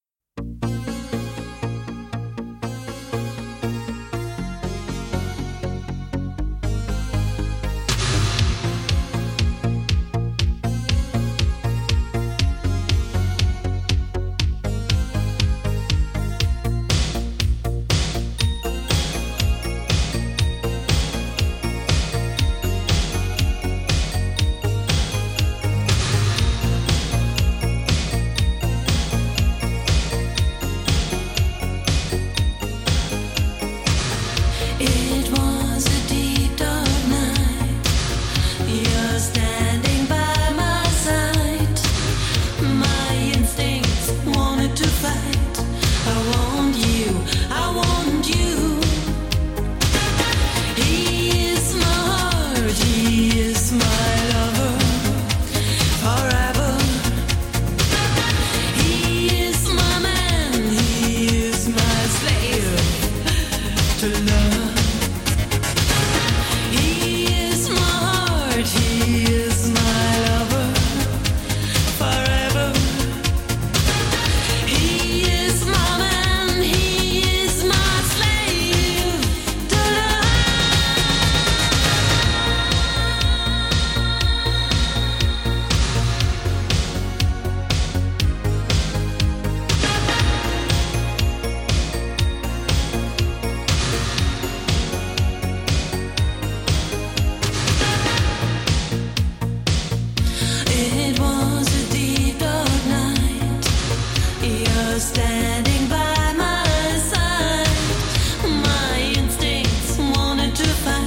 デケデケと迫るシンセベースや切なげな歌唱